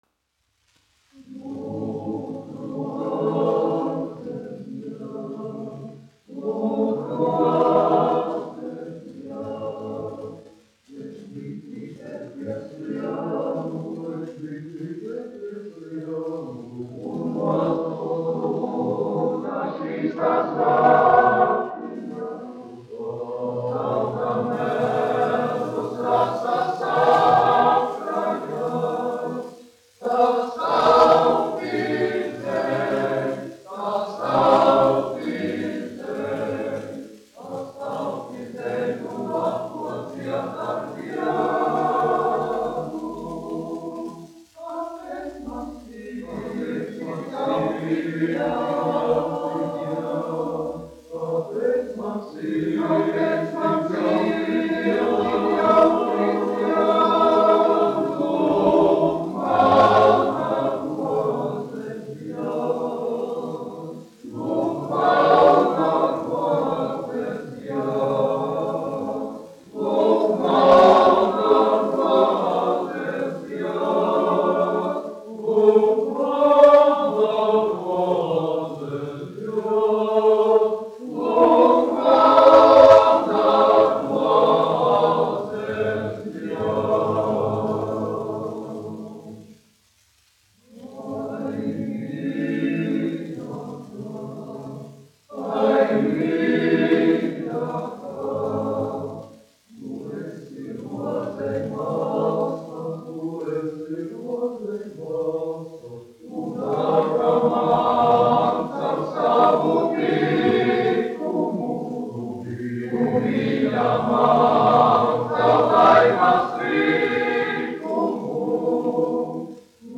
Dziedonis (koris : Rīga, Latvija), izpildītājs
1 skpl. : analogs, 78 apgr/min, mono ; 25 cm
Kori (vīru)
Skaņuplate